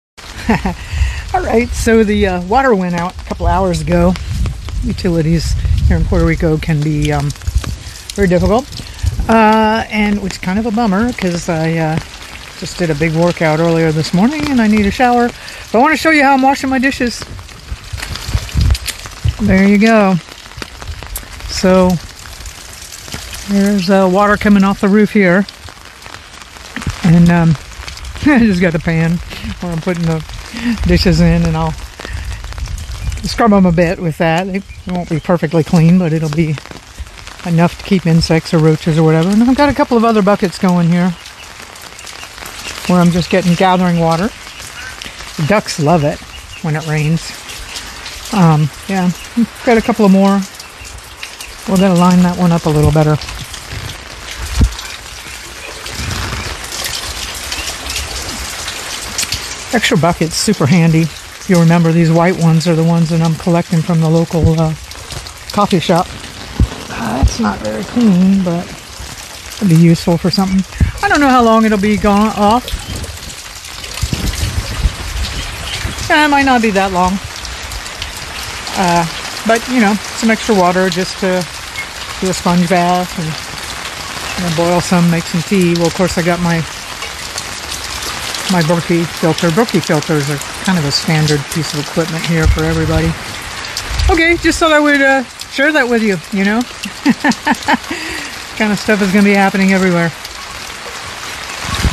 washing-dishes-in-the-rain.mp3